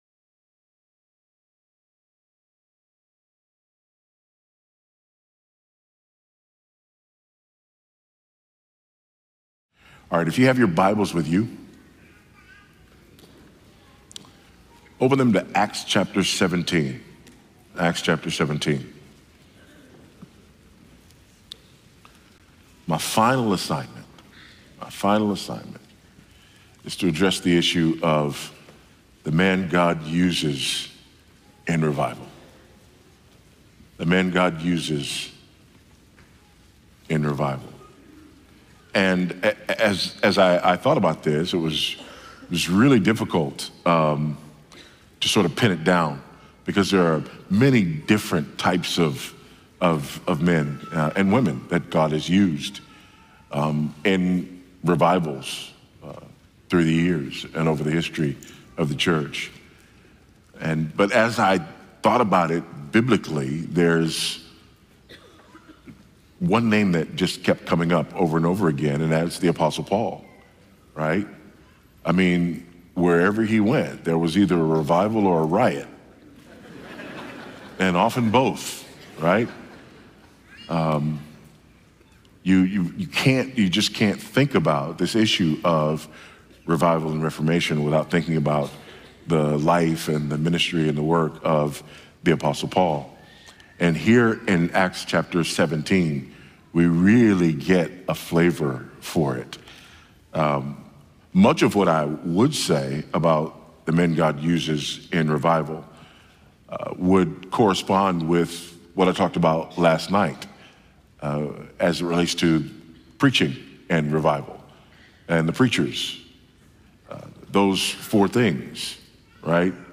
O Lord!" 2025 National Founders Conference in Fort Myers, Florida.
Sermons